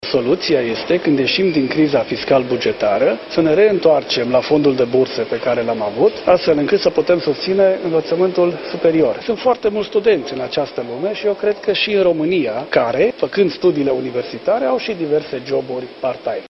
Ministrul Educației, Daniel David: „Sunt foarte mulți studenți care, în acest moment, făcând studiile universitare, au și diverse joburi part-time”